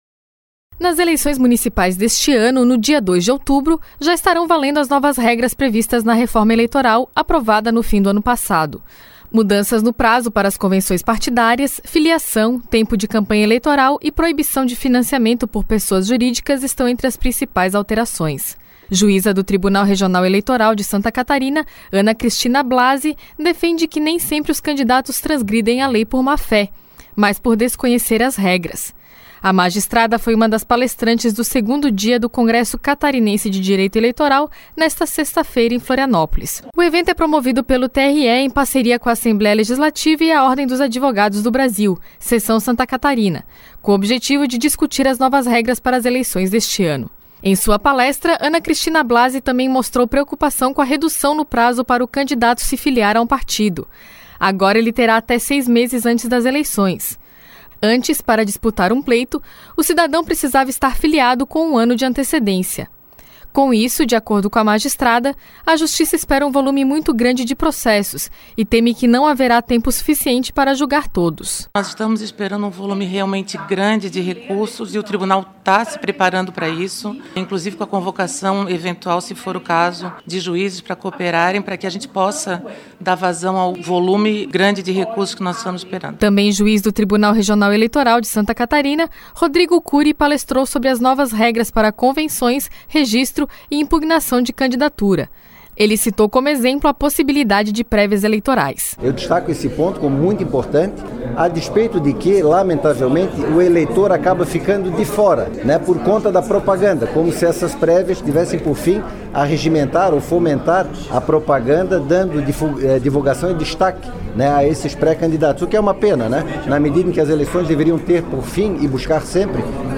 Congresso Catarinense de Direito Eleitoral é realizado no auditório do Tribunal de Justiça, em Florianópolis.
Entrevistas com:
- Ana Cristina Blasi, juíza do TRE/SC;
- Rodrigo Curi, juiz do TRE/SC.